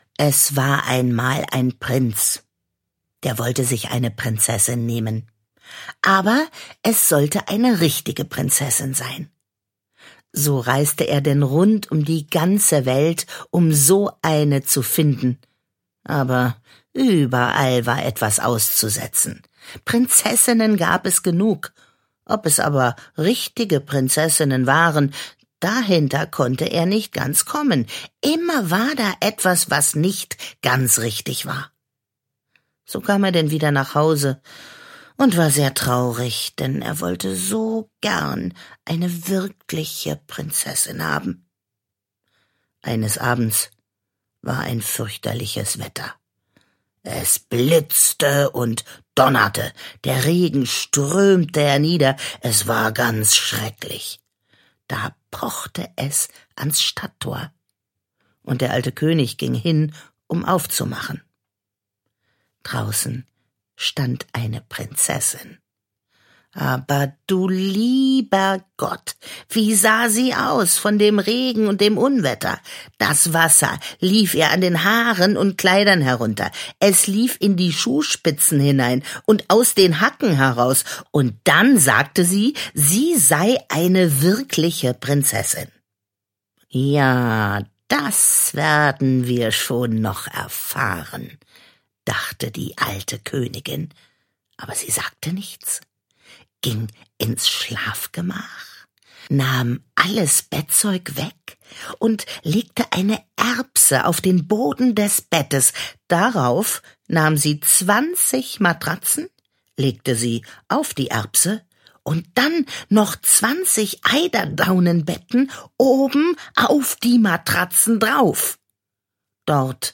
Schlagworte Hörbuch; Lesung für Kinder/Jugendliche • Hörbuch; Märchen/Sagen • Kinder/Jugendliche: Märchen, Sagen, Legenden • Märchen aus aller Welt • Märchen der Brüder Grimm • Märchen von Hans Christian Andersen